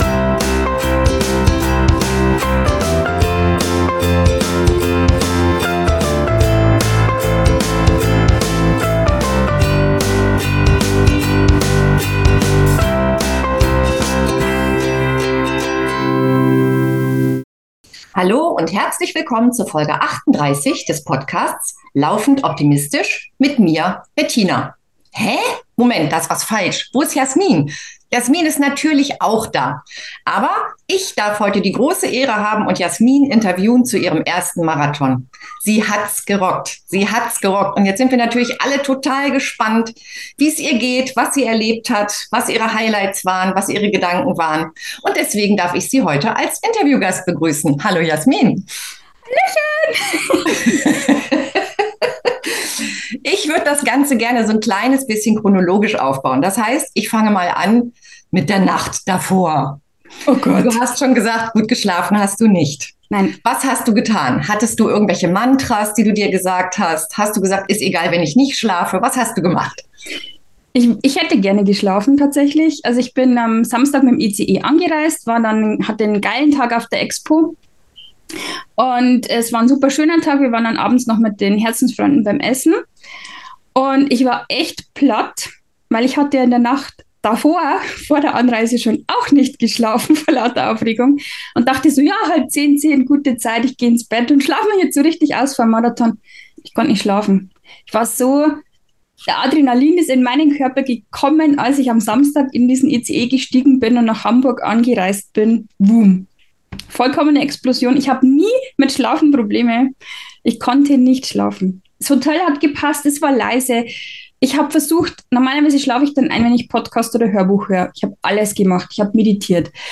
038_Marathon_Interview.mp3